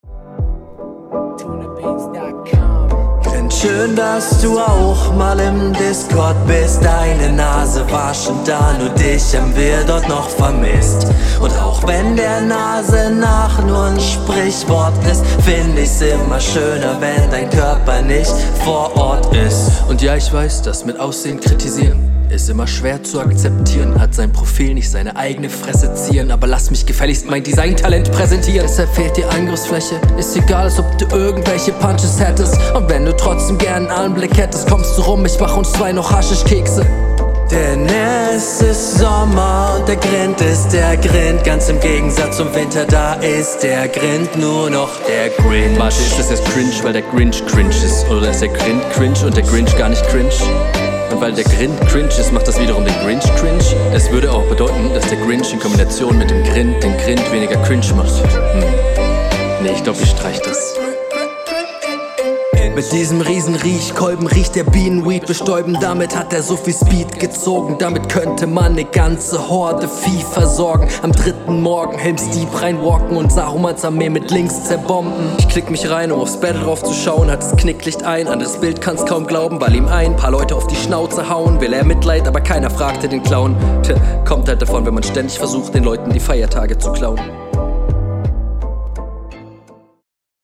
Kommt mit den Variationen auch einfach gut auf den Beat.
"Gesang" am Anfang find ich gut, auch wenns schlecht abgemischt ist.